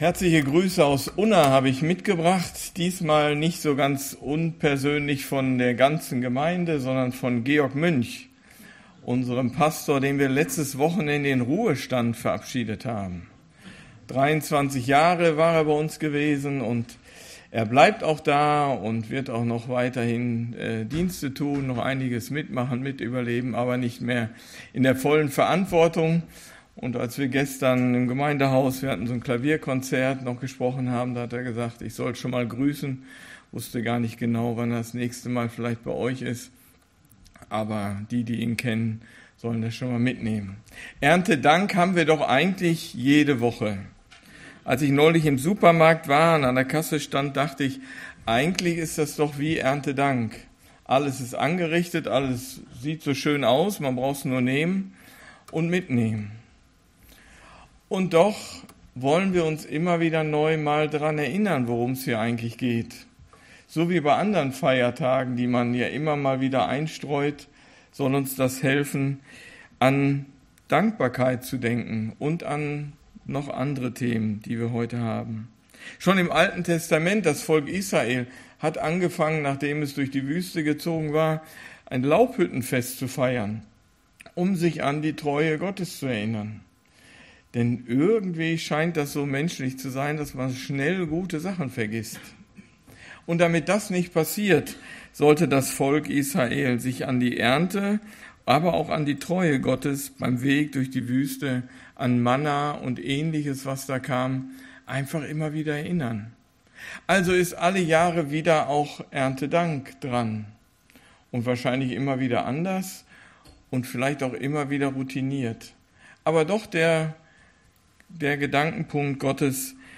Evangelisch-Freikirchliche Gemeinde Borken - Predigten anhören